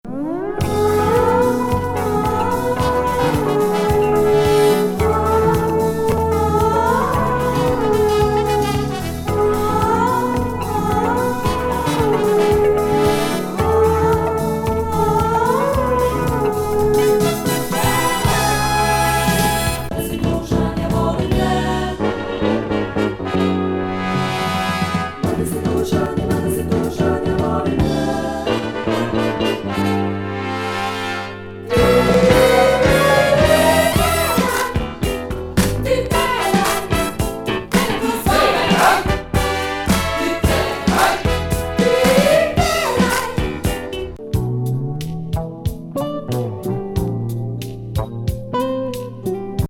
ユーゴスラビアのソウル・ジャズ・ロック・グループ82年作。
スペーシー・エレクトロニクスなエモりディスコ・グルーヴ
女宇宙気味ファンク
メロウ・フュージョン